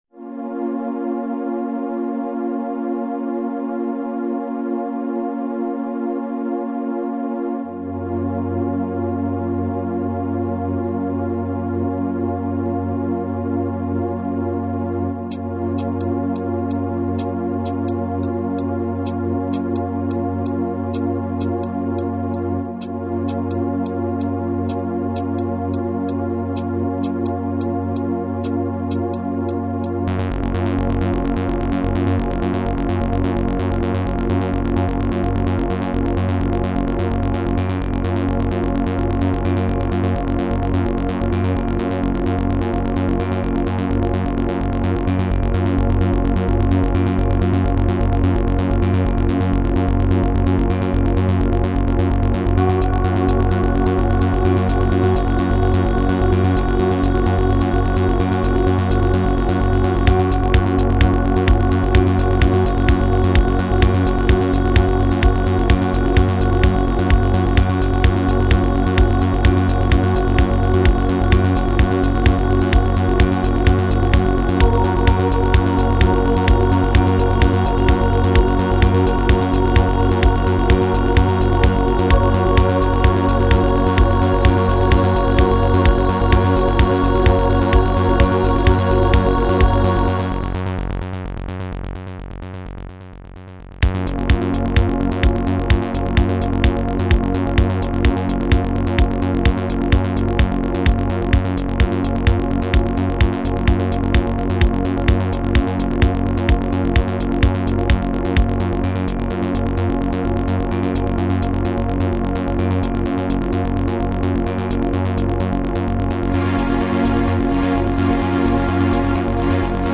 Ambient - 5:55 - BPM 128